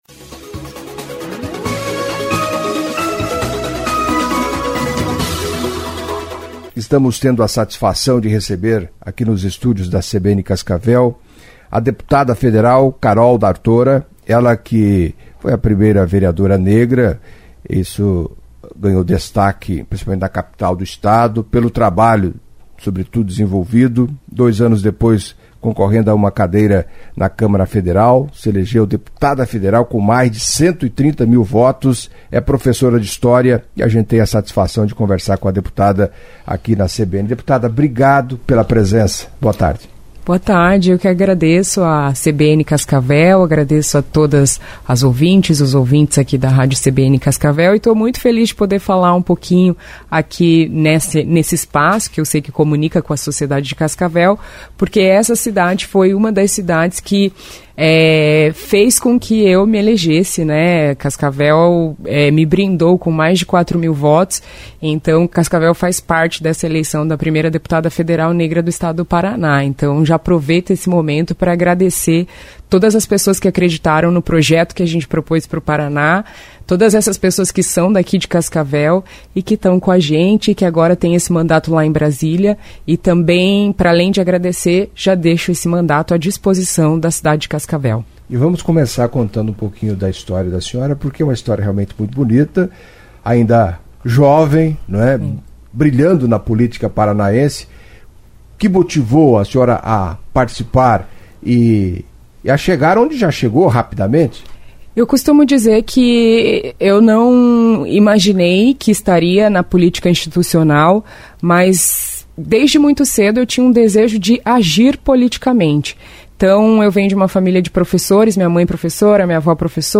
Em entrevista à CBN Cascavel nesta sexta-feira (24) a deputada federal paranaense, Carol Dartora (PT), falou das dificuldades para a mulher ingressar na política e dos obstáculos em geral enfrentados, principalmente, pela mulher negra.